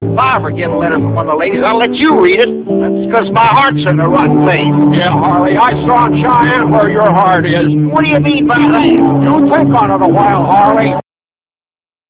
Myheart.real audio-13kbHarley talking about his heart being in the right place!...but john seems to think his heart was someplace else!